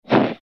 shovel.mp3